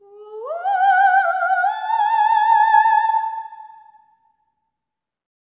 OPERATIC02-R.wav